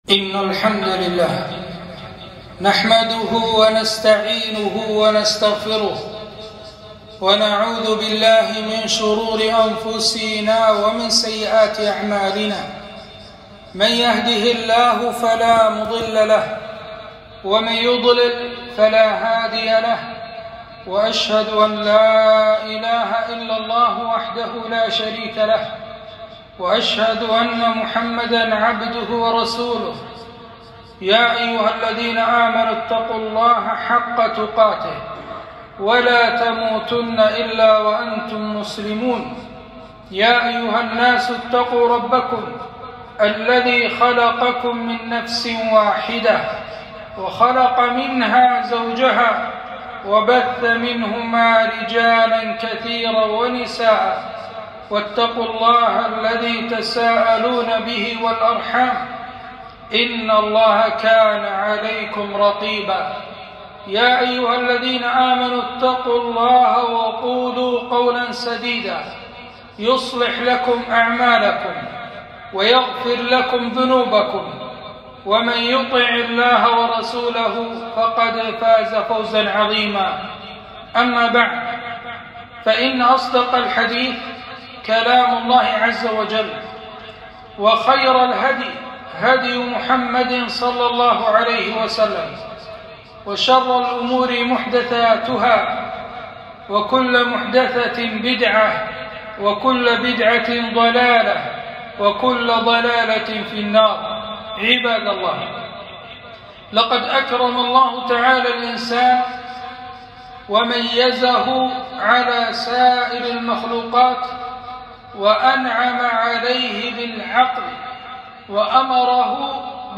خطبة - خطورة المخدرات والمسكرات على الفرد والمجتمع